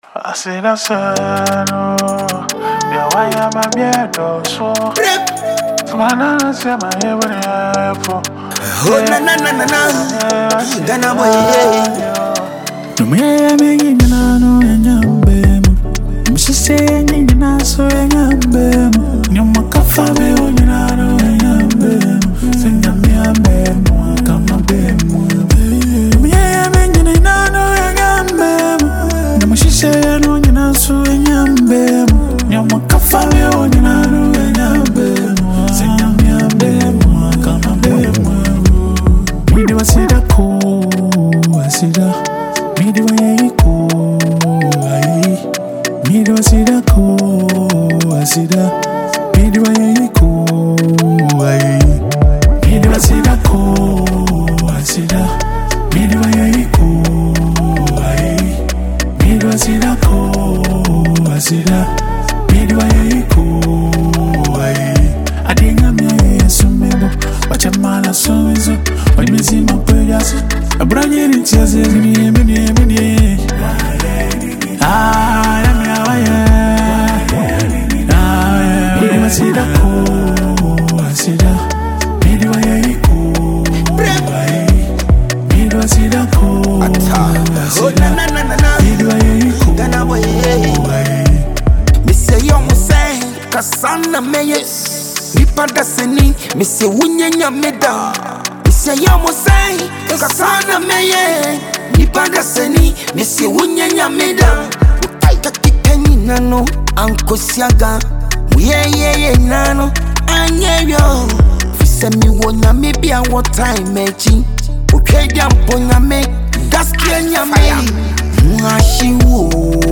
soul-stirring gospel song
This uplifting track